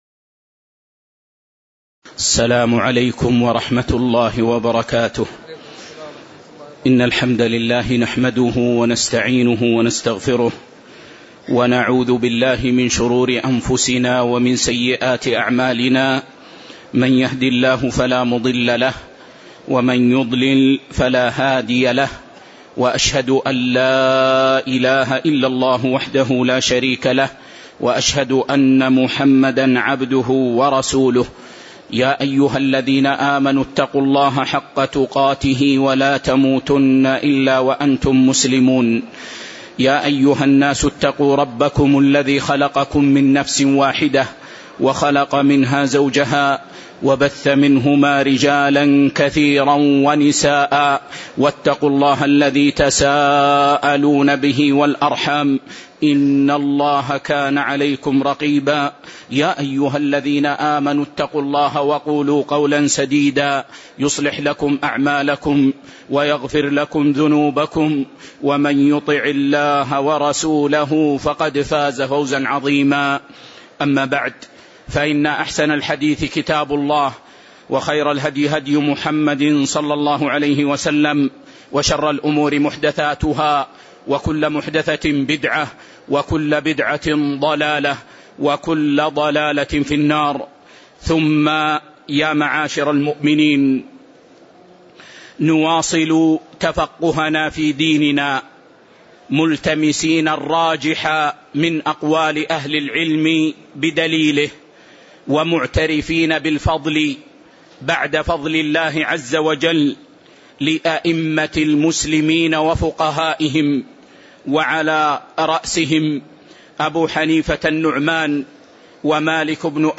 تاريخ النشر ١٨ ربيع الأول ١٤٣٨ هـ المكان: المسجد النبوي الشيخ